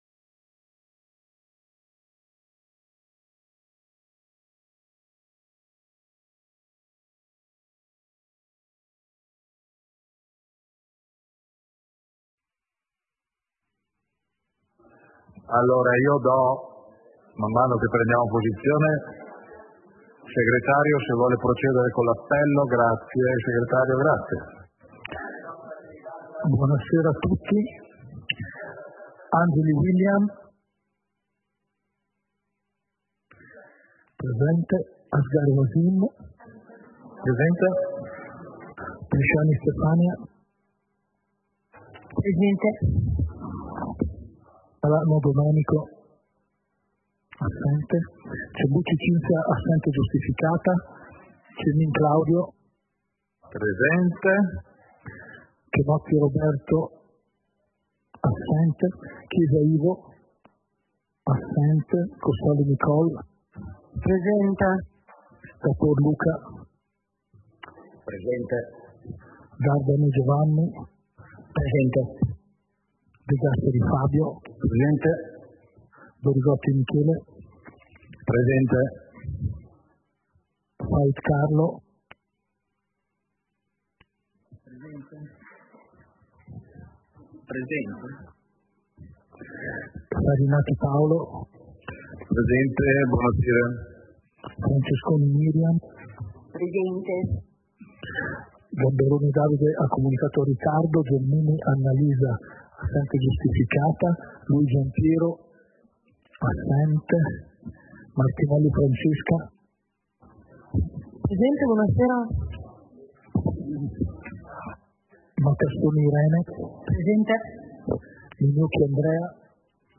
Seduta del consiglio comunale - 18 novembre 2025